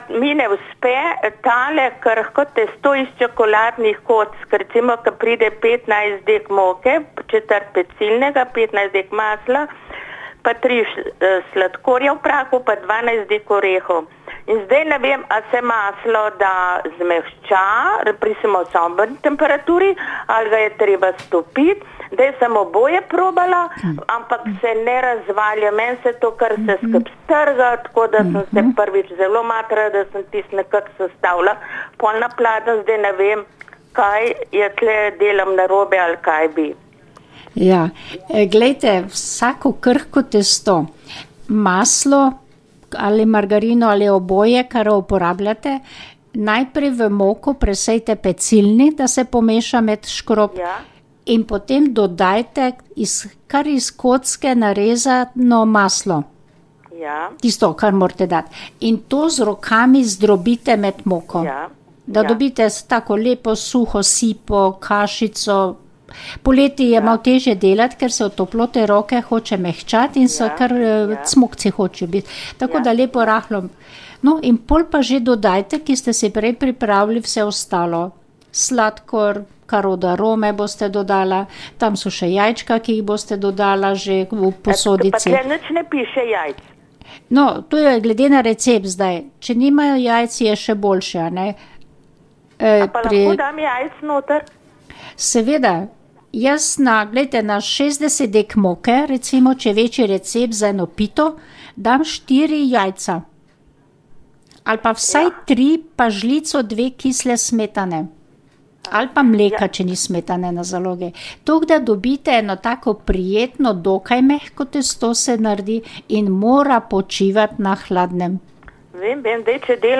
Poslušalka je spraševala, kako se naredi krhko testo, predvsem v kakšni obliki dodamo maslo. Sestra ji je svetovala, naj najprej v moko preseje pecilni prašek, nato doda narezano maslo ter to z rokami zdrobi.